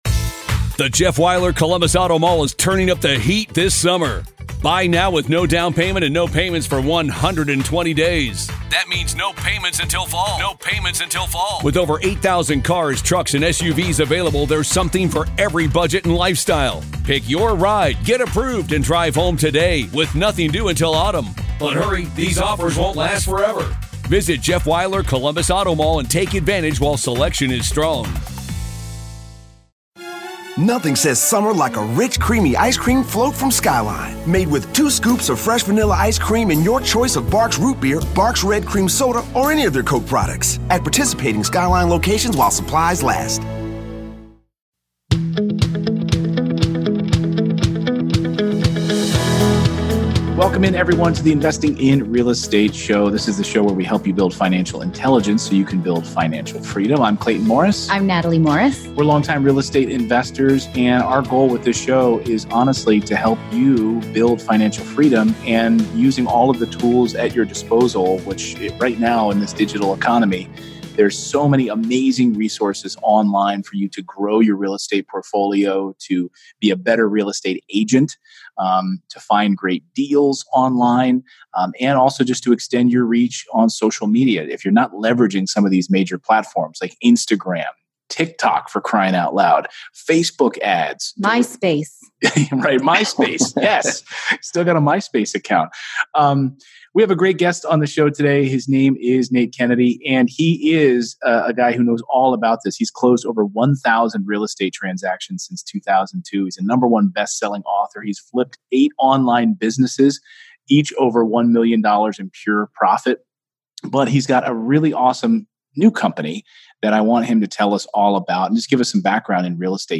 In this interview, he’s sharing how to build a strategy around marketing and branding, the power of having genuine conversations, and how to build your presence online.